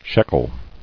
[shek·el]